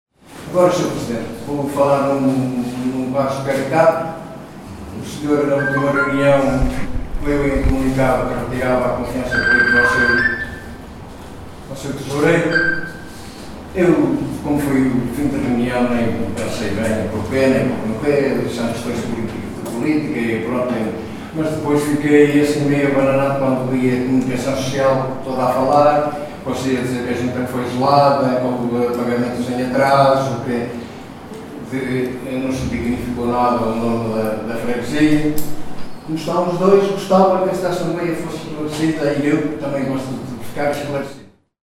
No início da noite desta segunda-feira, 30 de junho, realizou-se na sede da Junta de Freguesia de Touro (Vila Nova de Paiva), a Assembleia de Freguesia referente a junho de 2025, onde no Período Antes da Ordem do Dia, Amândio Salvador, elemento da Assembleia do Partido Social Democrata (PSD) questionou Mário Morgado, Presidente da Junta de Freguesia, sobre o comunicado que veio à estampa na comunicação social, que dava conta da retirada de confiança ao Tesoureiro Jorge Morais.